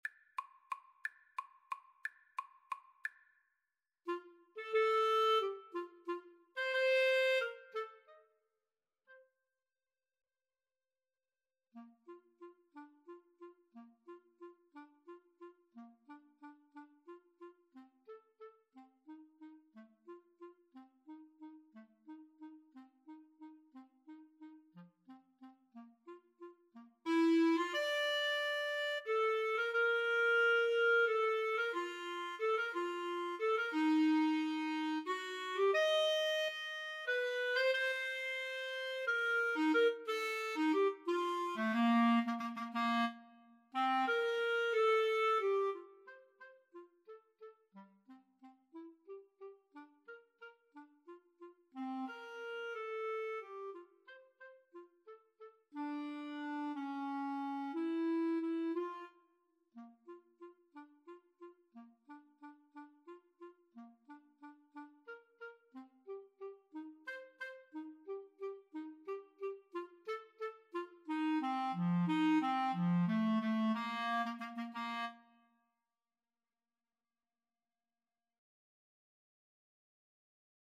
Bb major (Sounding Pitch) C major (Clarinet in Bb) (View more Bb major Music for Clarinet Duet )
3/4 (View more 3/4 Music)
~ = 180 Tempo di Valse
Clarinet Duet  (View more Intermediate Clarinet Duet Music)
Classical (View more Classical Clarinet Duet Music)